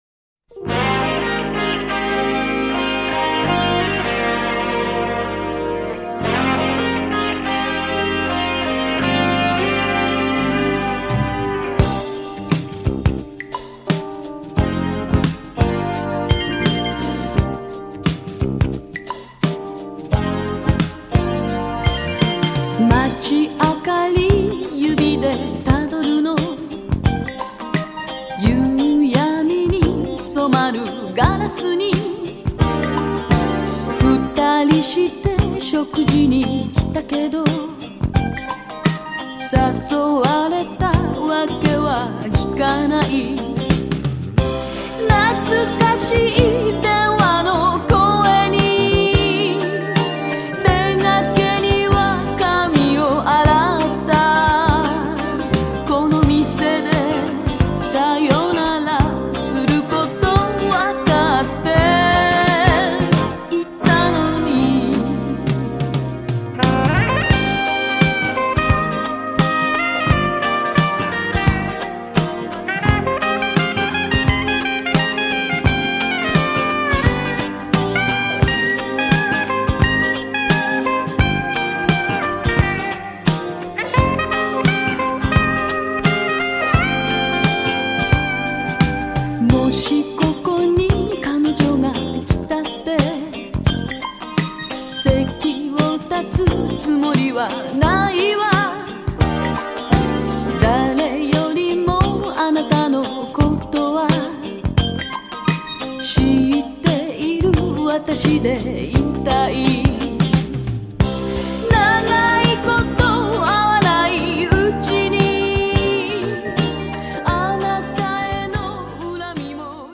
ジャンル(スタイル) JAPANESE POP CLASSIC / CITY POP